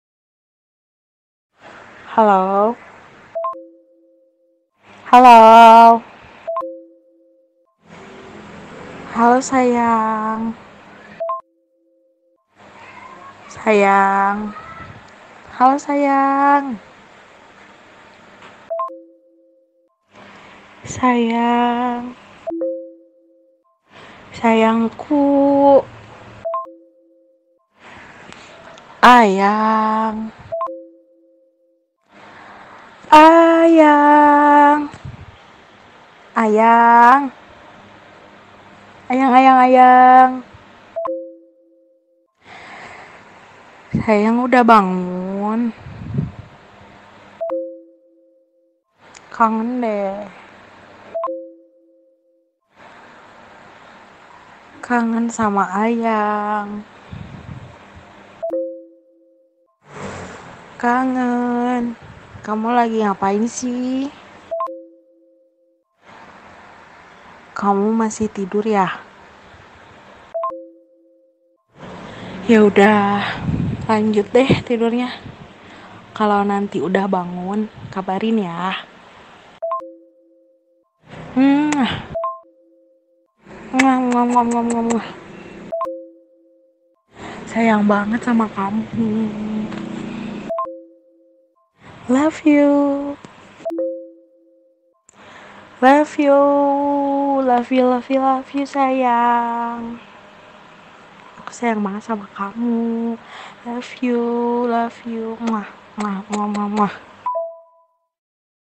VN Suara Wanita Bilang Halo Sayang
Kategori: Suara manusia
Suara imut, bisa di-download dalam format mp3, juga cocok untuk notifikasi WA.
vn-suara-wanita-bilang-halo-sayang-id-www_tiengdong_com.mp3